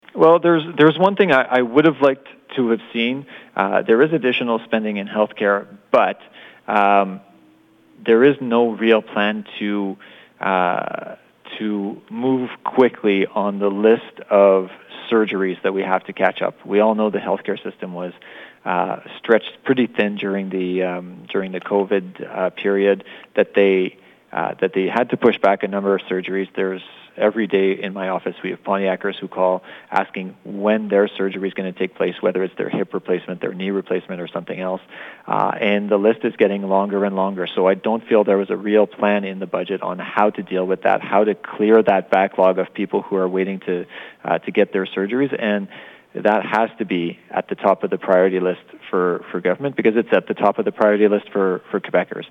Pontiac MNA André Fortin spoke with CHIP 101.9 about some of the highlights (and lowlights) of this year’s provincial budget, which was tabled last week.